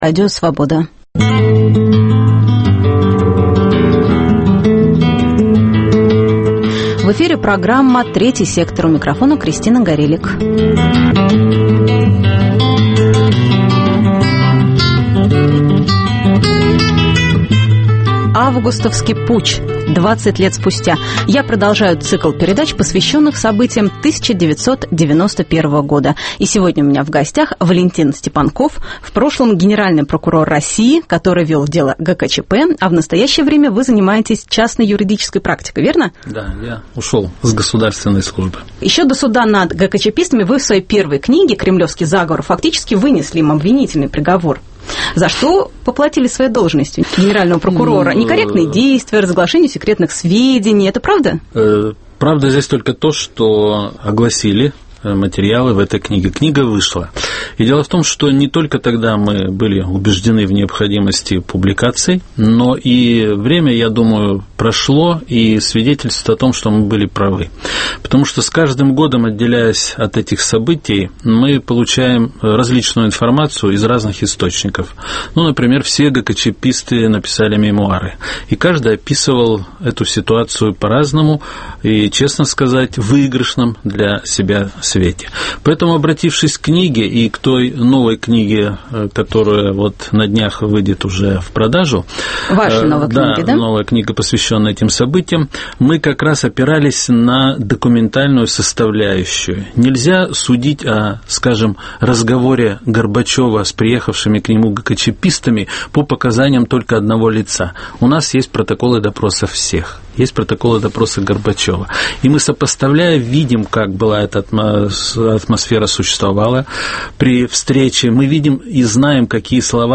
В гостях: Валентин Степанков, генеральный прокурор России в 1991 году, вел дело ГКЧП.